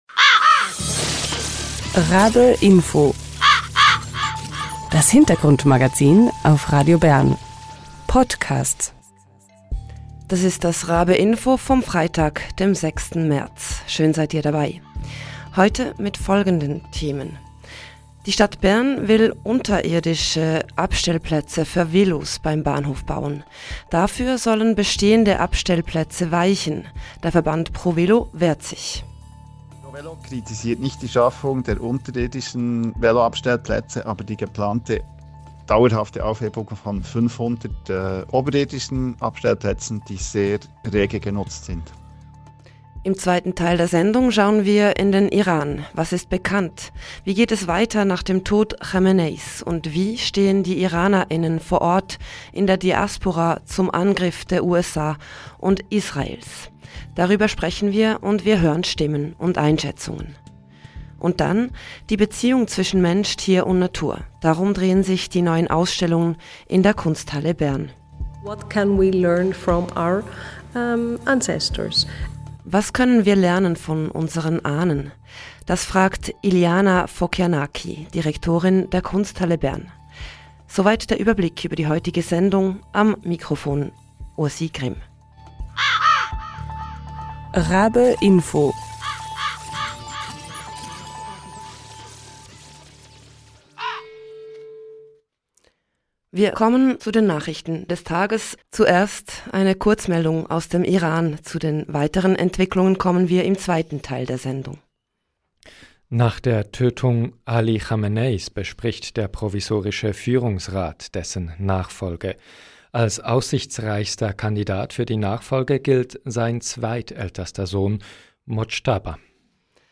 Und wie stehen die Iraner*innen vor Ort und in der Diaspora zum Angriff der USA und Israels? Wir hören Stimmen und Einschätzungen. Und ausserdem: Die neuen Ausstellungen in der Kunsthalle Bern drehen sich um die Beziehung zwischen Mensch, Tier und Natur.